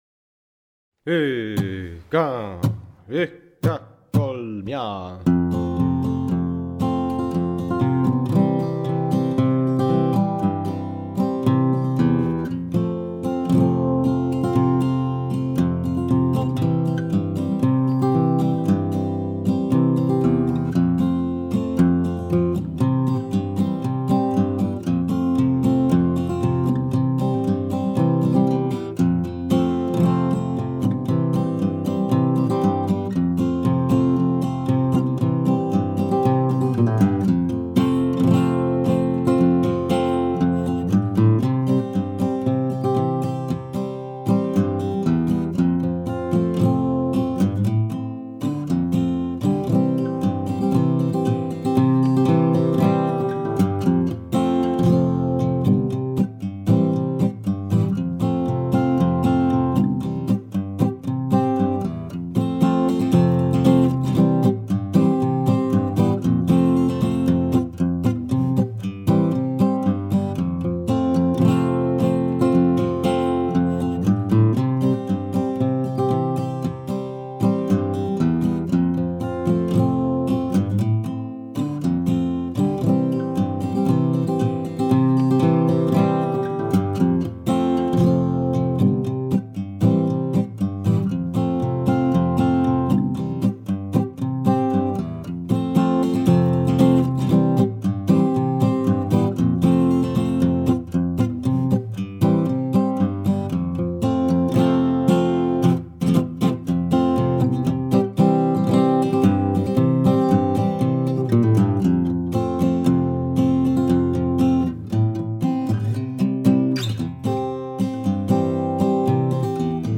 Polka "Litsu pääle"